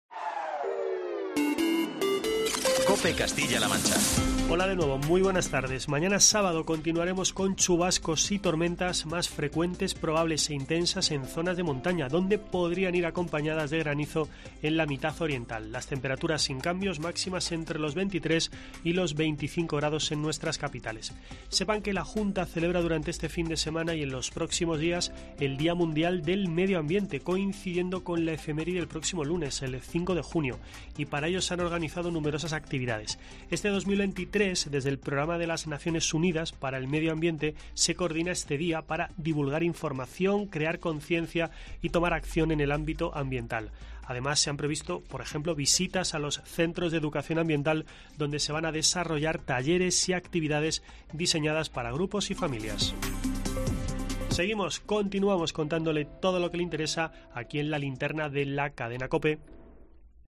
Último boletín